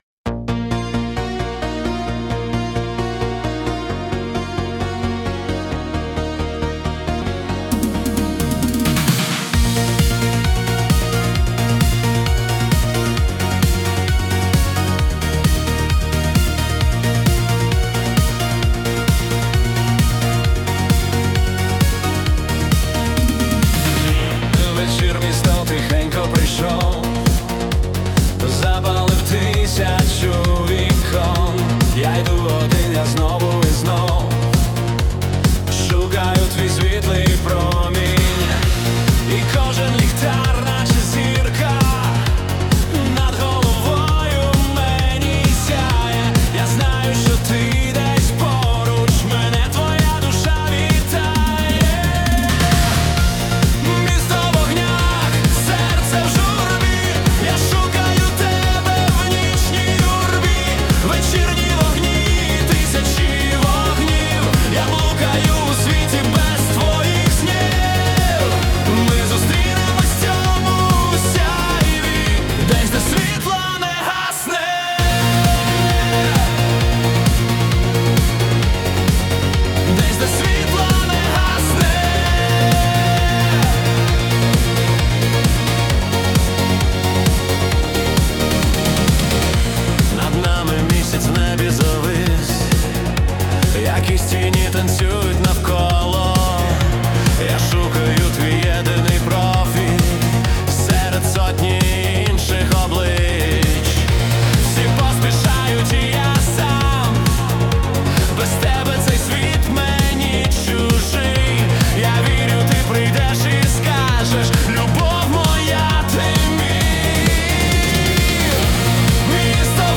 🎵 Жанр: Synth-pop